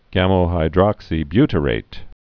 (gămə-hī-drŏksē-bytə-rāt)